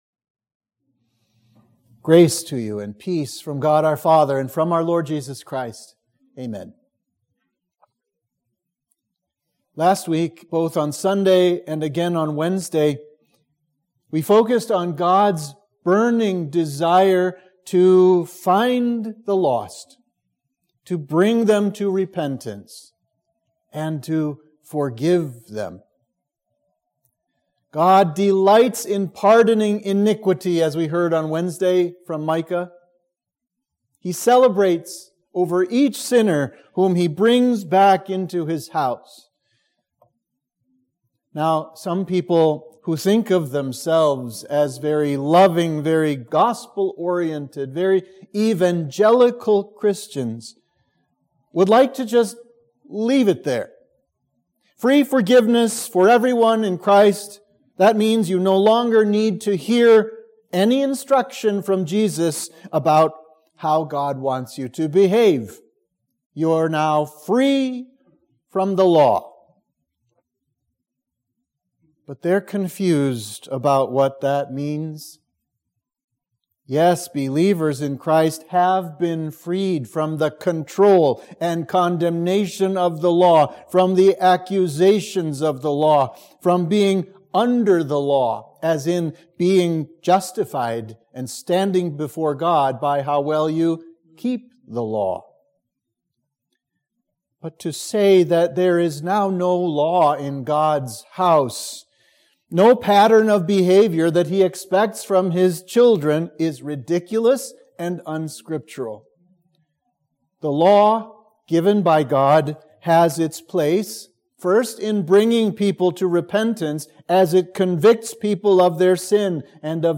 Sermon for Trinity 4